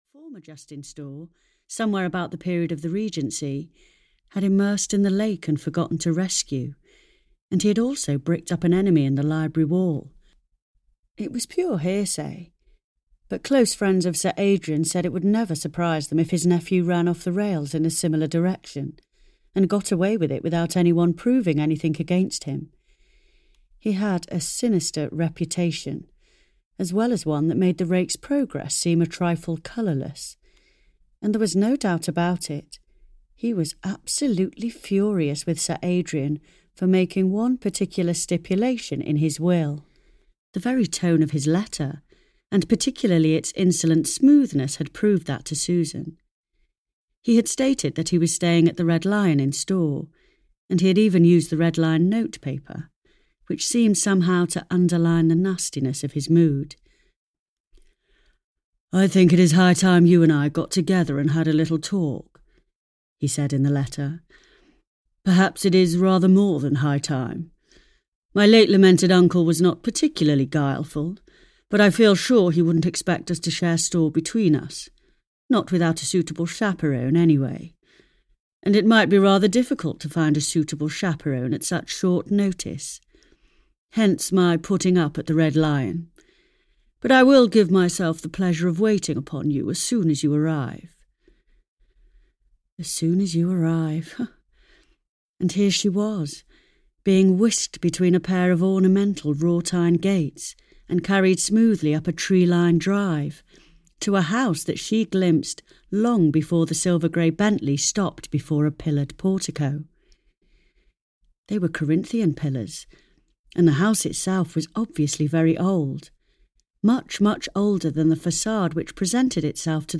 Dangerous Lover (EN) audiokniha
Ukázka z knihy